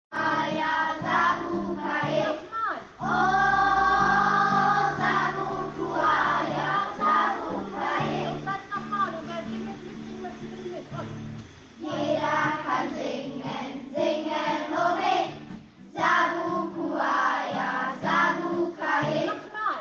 Auftritt der Chor-AG im Haus Heidbeck
Fast 20 Kinder sind ins Pflegeheim der Stader Betreuungsdienste gegangen und haben vor den Bewohnern ein kleines Konzert gegeben.
Gitarrenklängen
Dann wurde noch ein Kanon präsentiert und zum Schluss sangen die Kinder ihr selbstgedichtetes „Chor-AG“ Lied vor: